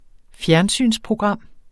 Udtale [ ˈfjæɐ̯nˌsyns- ]